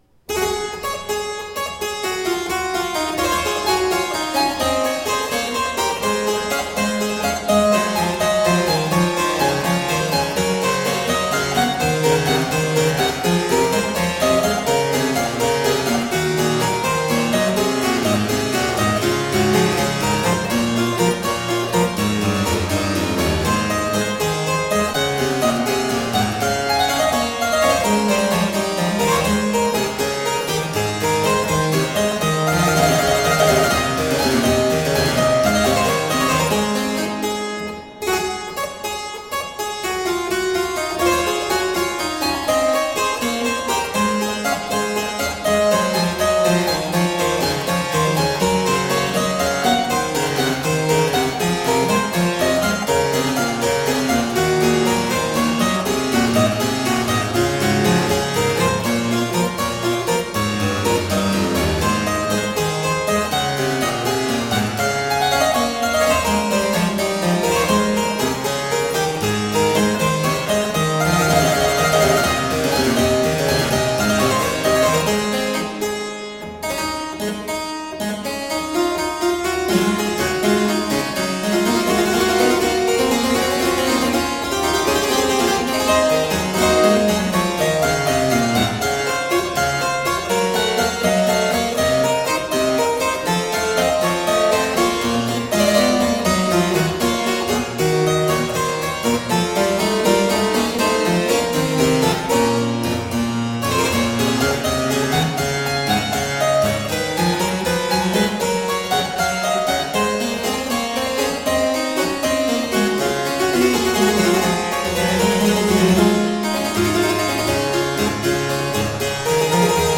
Bach on the harpsichord - poetic and expressive.